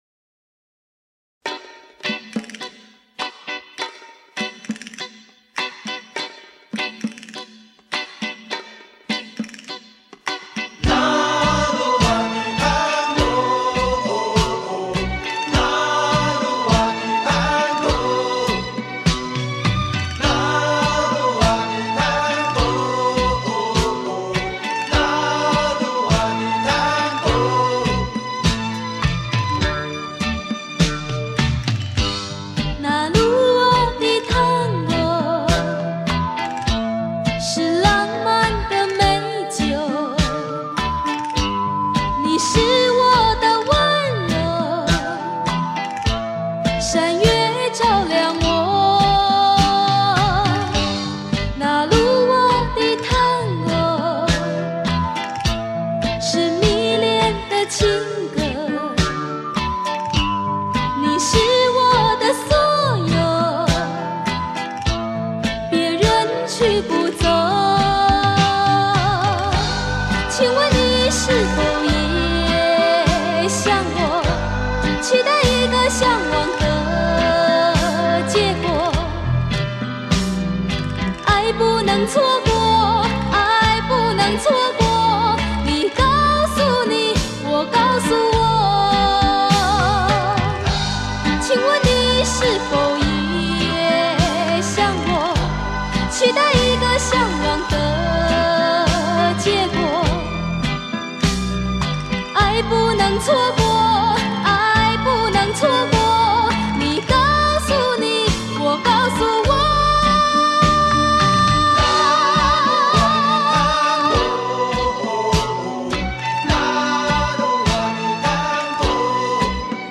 盒带全新数码采样精工制作，音质上佳。